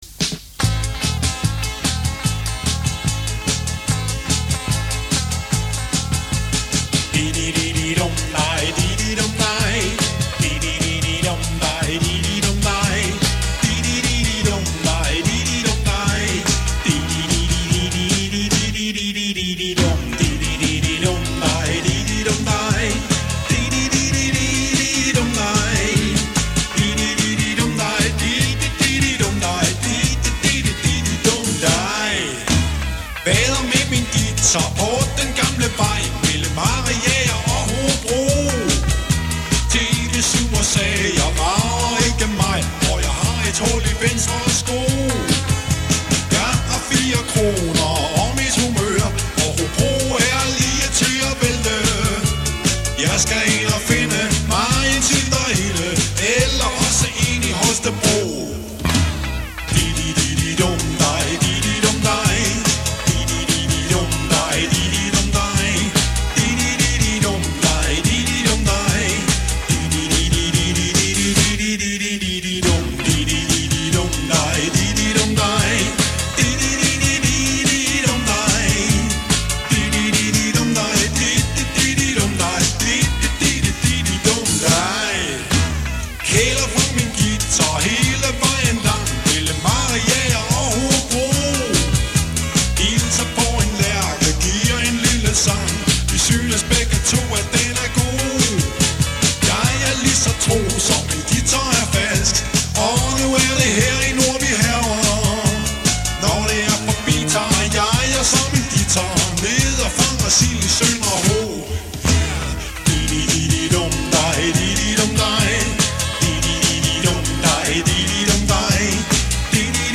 trommer